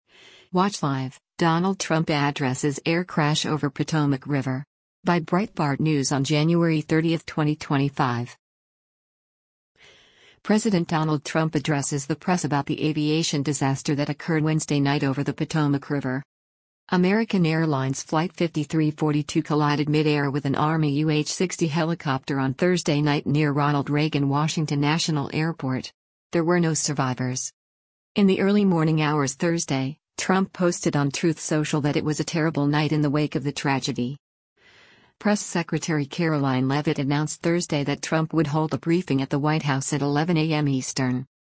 President Donald Trump addresses the press about the aviation disaster that occurred Wednesday night over the Potomac River.